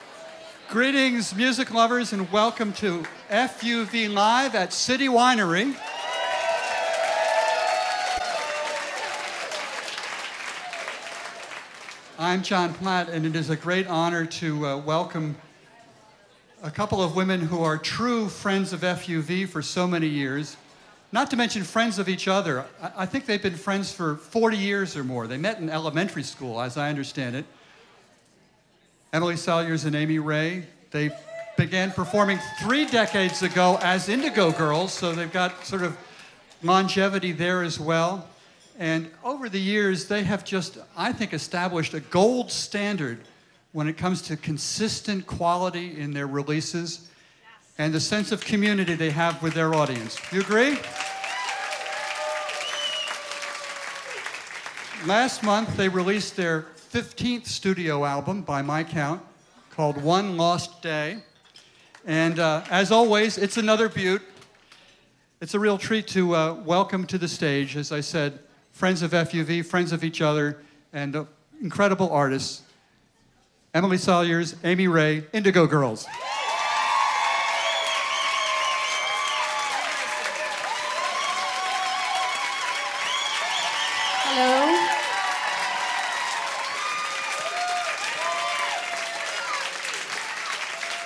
lifeblood: bootlegs: 2015-07-23: fuv live at city winery - new york, new york
(recorded from the webcast)